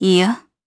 Valance-Vox-Deny_jp.wav